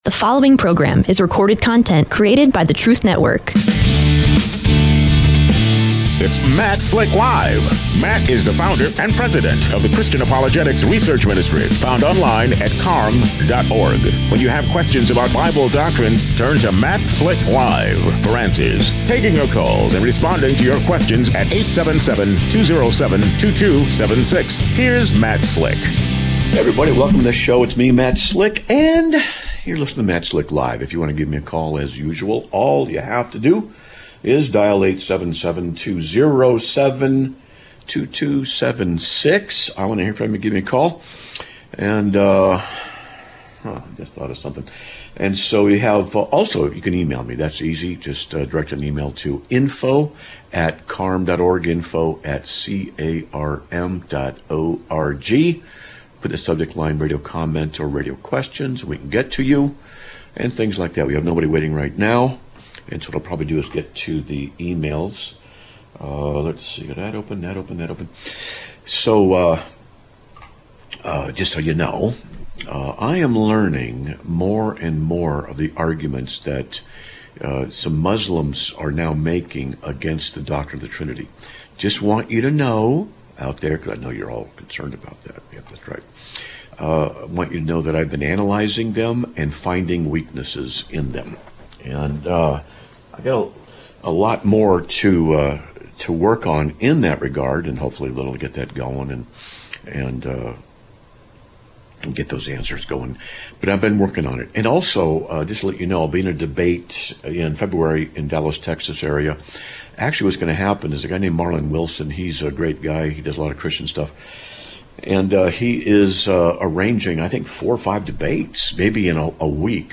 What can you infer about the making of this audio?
Live Broadcast of 09/04/2025